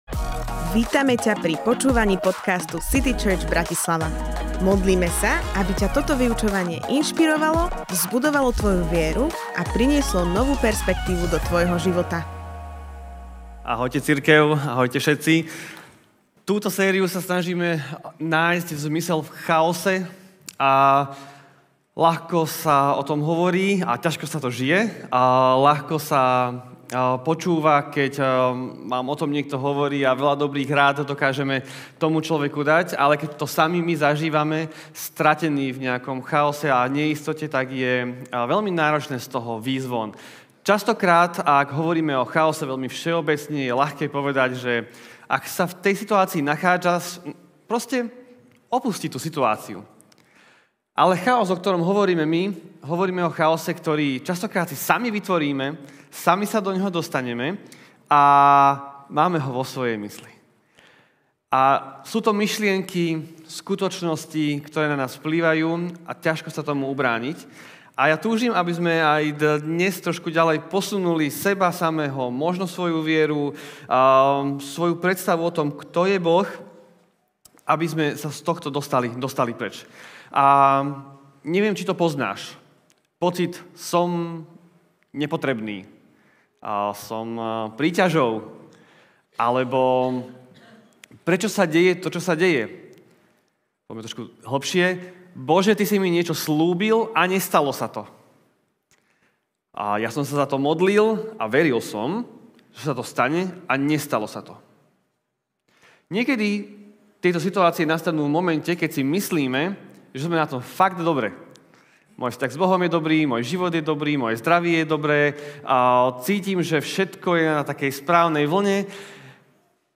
Moja nevera Kázeň týždňa Zo série kázní